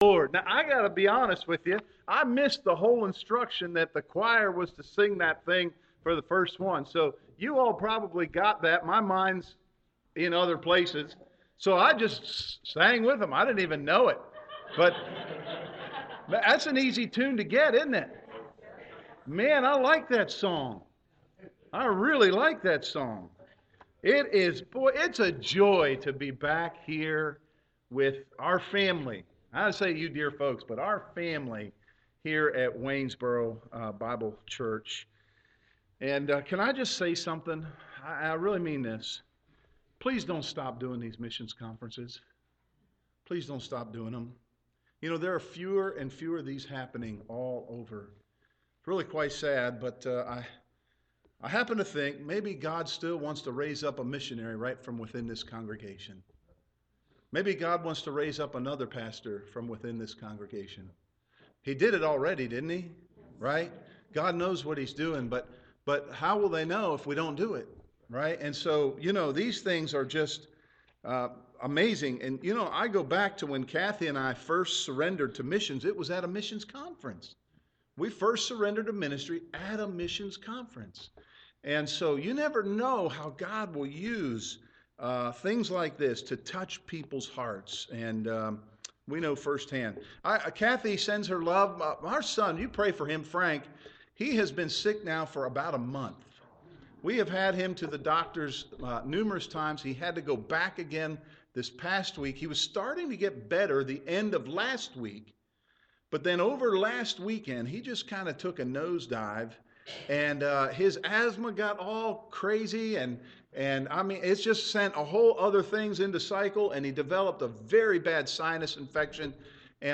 2023 Missions Conference
Sermon